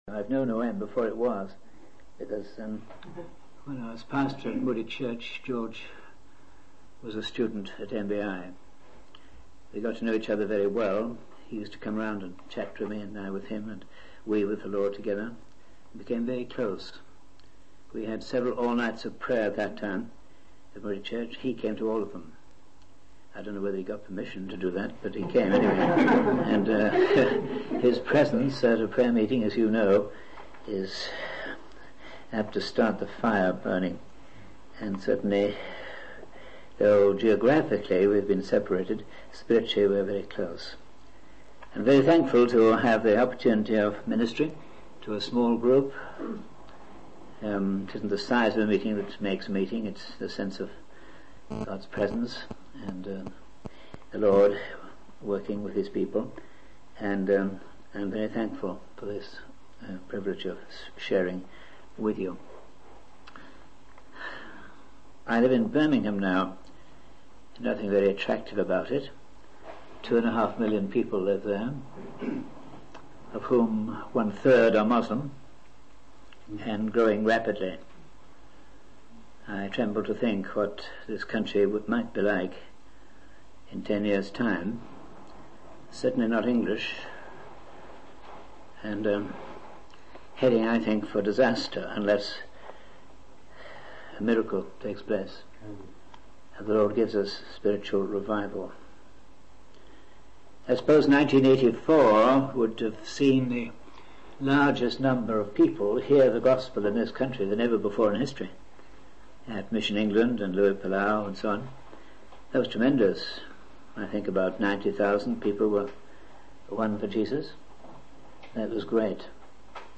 In this sermon, the speaker uses the analogy of a plane struggling to take off to illustrate the meaning of the gospel.